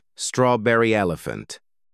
Elephant Sound Effects MP3 Download Free - Quick Sounds